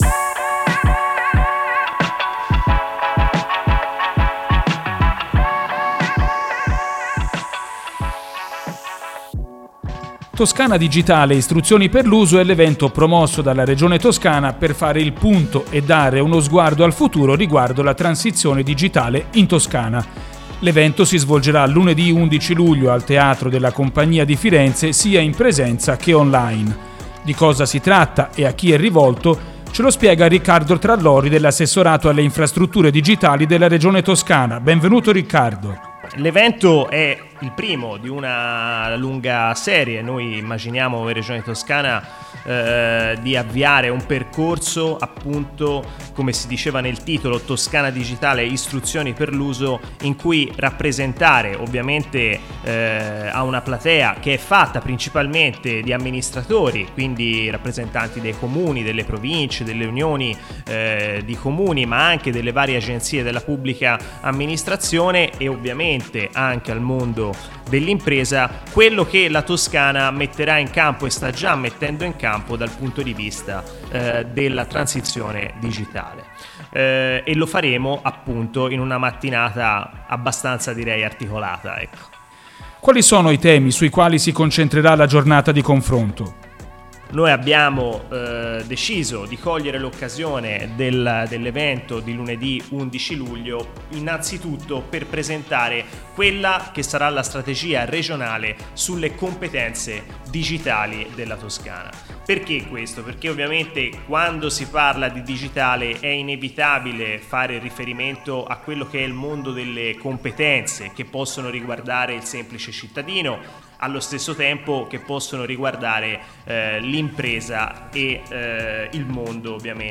Lunedì 11 luglio al Teatro della Compagnia di Firenze